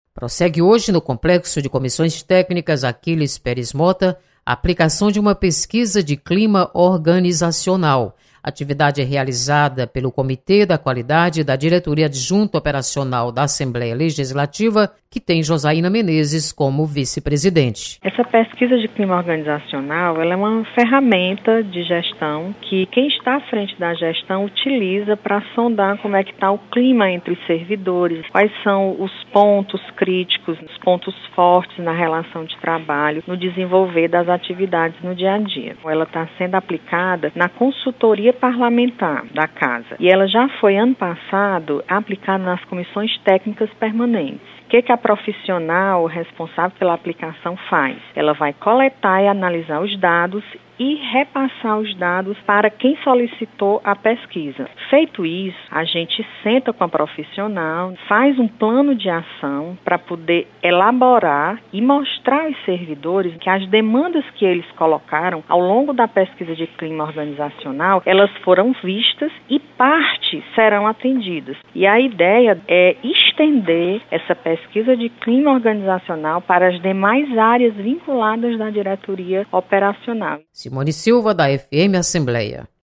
Diretoria Adjunta Operacional aplica pesquisa organizacional. Repórter